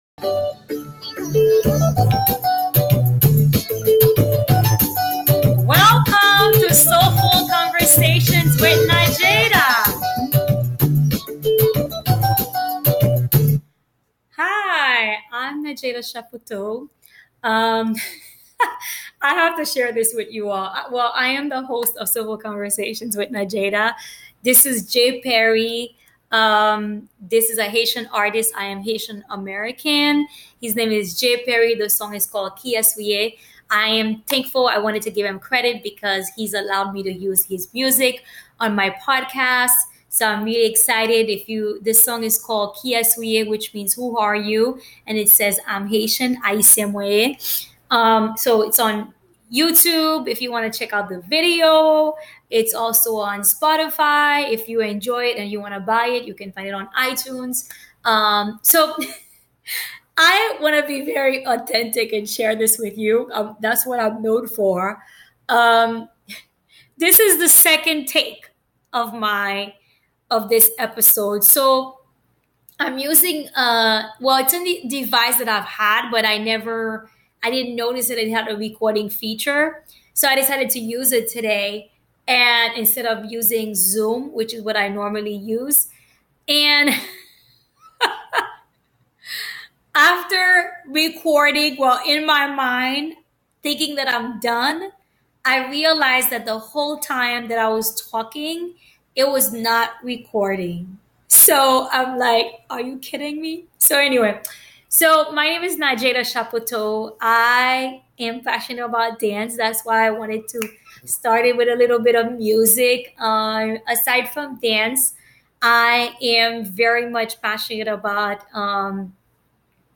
Before I talk about this week’s episode I want to apologize in advance for the sound being bad during 2 parts of the episode but because they both lasted only 1 minute each.